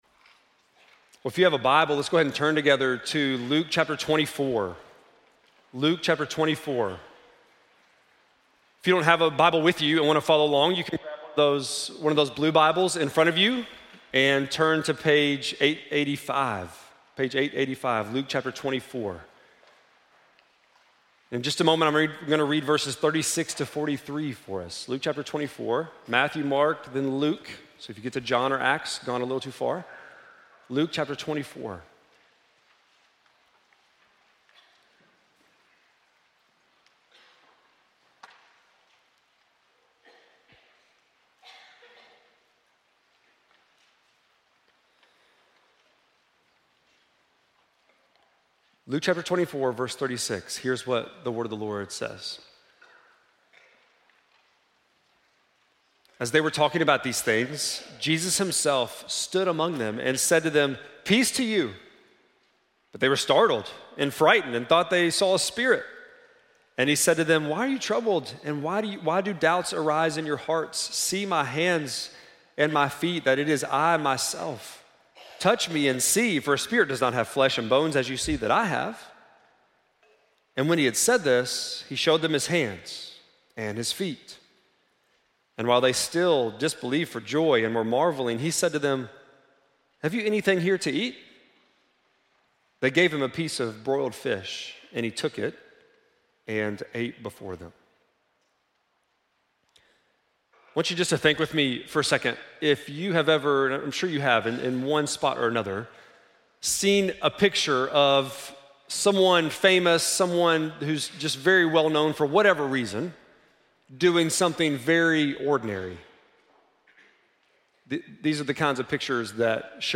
4.20-sermon.mp3